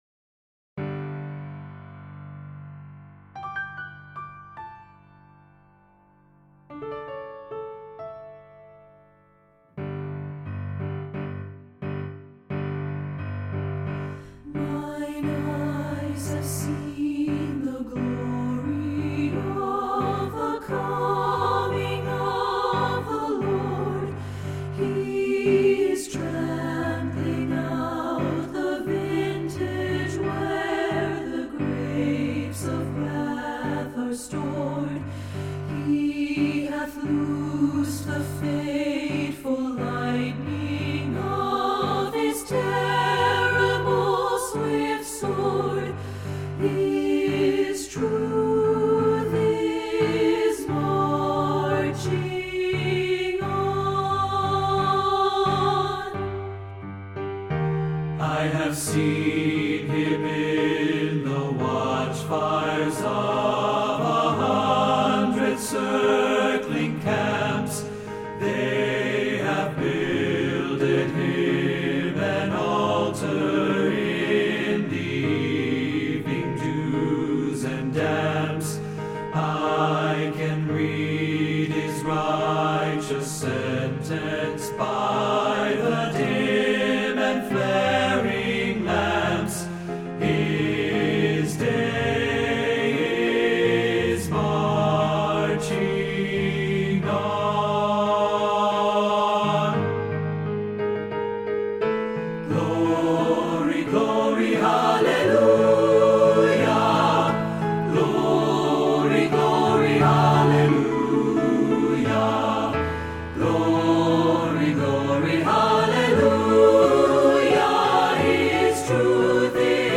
patriotic closer for your choir
Choral